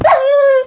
sounds / mob / wolf / death.ogg
death.ogg